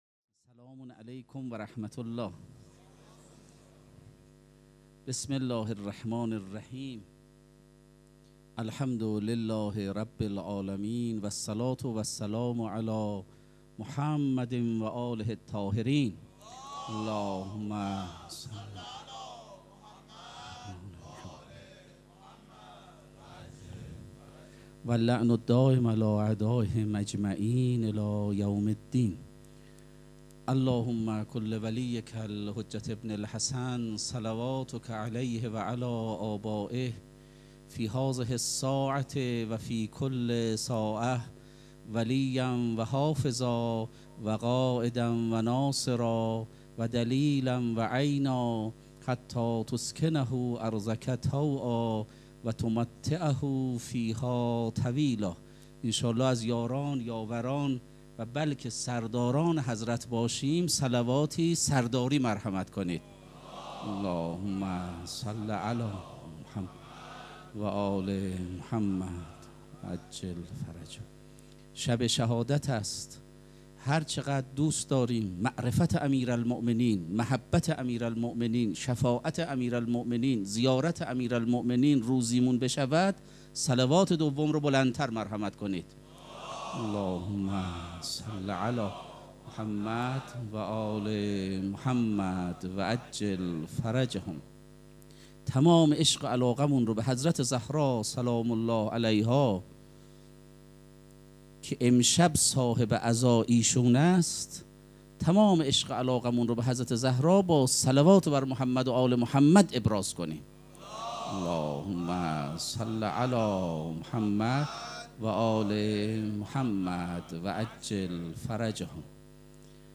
سخنرانی
پنجشنبه‌ ۱ اردیبهشت ۱۴۰۱ | ۱۹ رمضان ۱۴۴۳حسینیه ریحانة‌الحسین (سلام‌الله‌علیها)